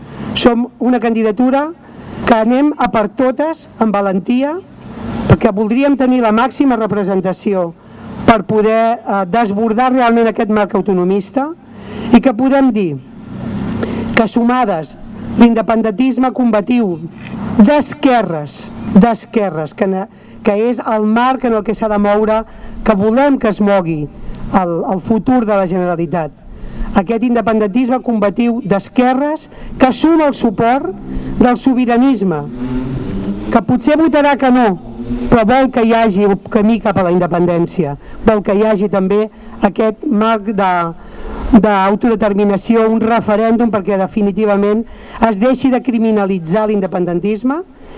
En un acte al Parc de Maria Regordosa de Ripollet, Dolors Sabater defensa el programa de la CUP-Guanyem com "una proposta rupturista, amb una aposta decidida per enfortir la xarxa pública de serveis i per un referèndum que no estigui perseguit ni criminalitzat".
Declaracions de Dolors Sabater: